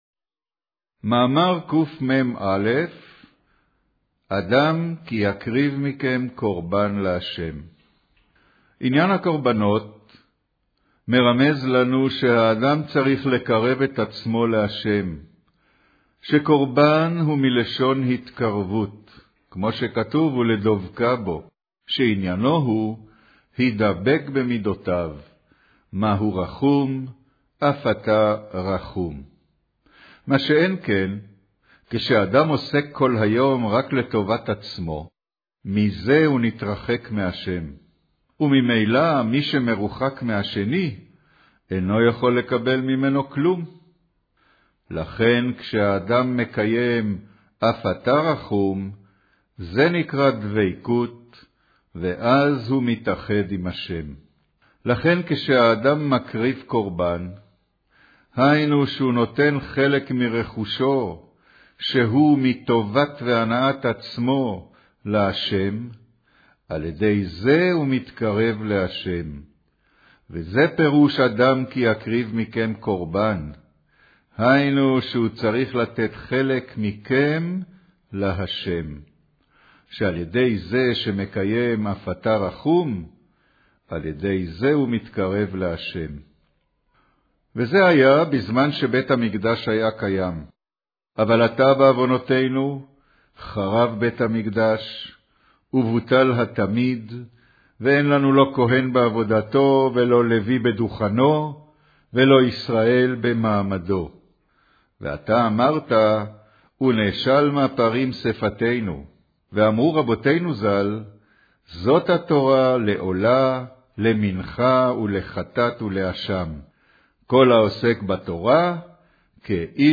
קריינות